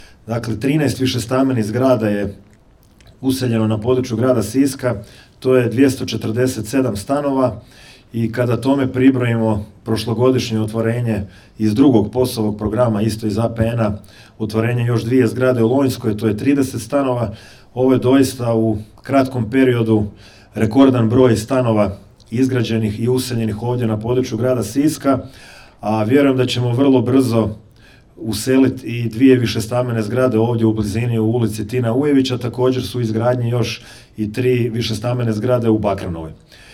Gradonačelnik Domagoj Orlić istaknuo je kako je u kratkom roku izgrađen i useljen rekordan broj stanova